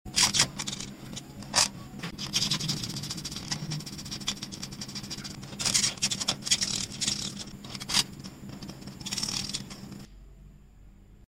ASMR Colors: Coloring a Heart sound effects free download
Watch every smooth glide of color and enjoy the relaxing sounds that make ASMR art so addictive.